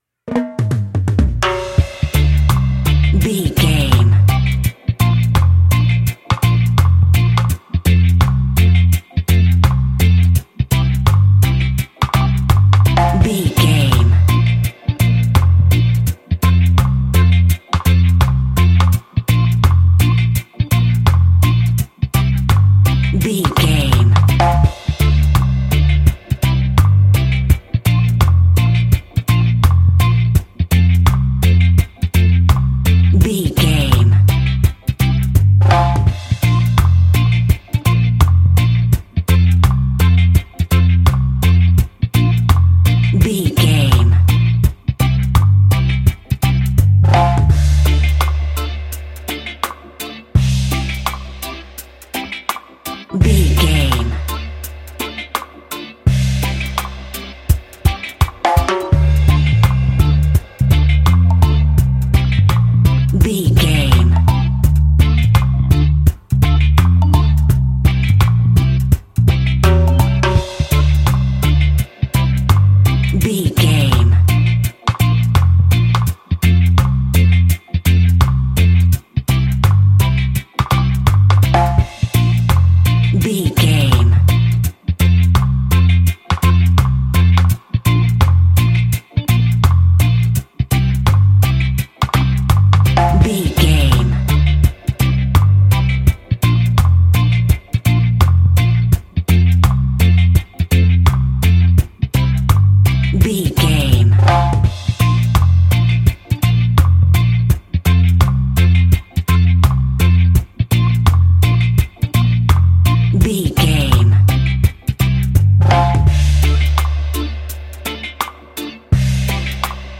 Classic reggae music with that skank bounce reggae feeling.
Aeolian/Minor
D
instrumentals
laid back
chilled
off beat
drums
skank guitar
hammond organ
percussion
horns